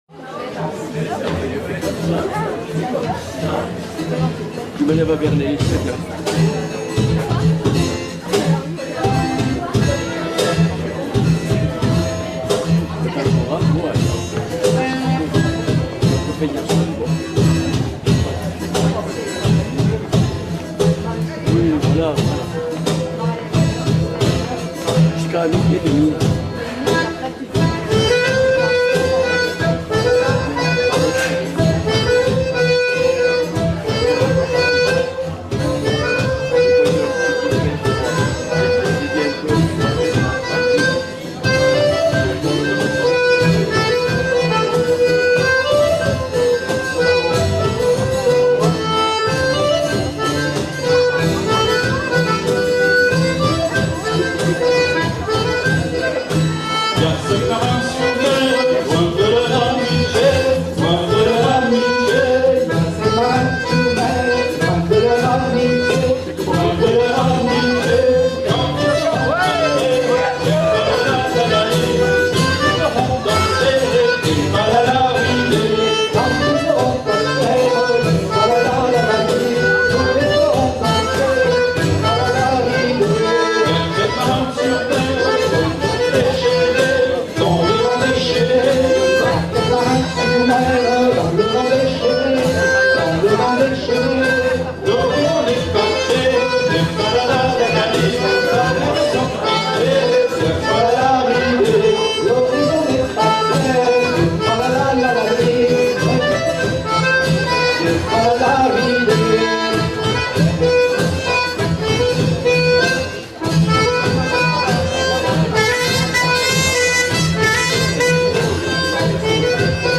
Hanter Dro